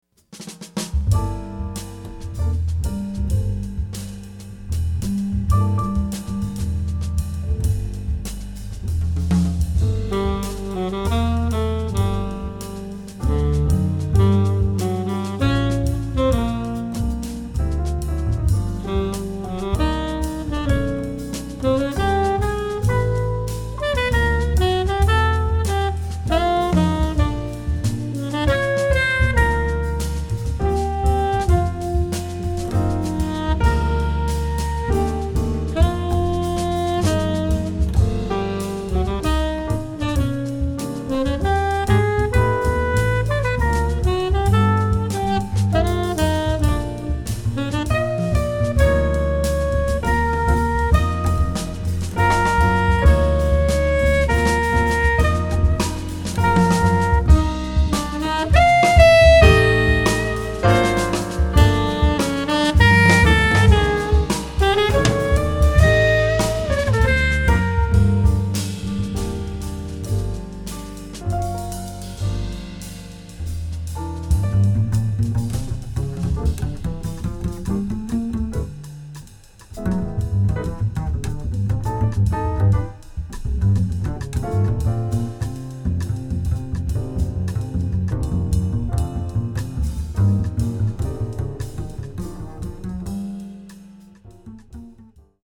on tenor
on bass
on drums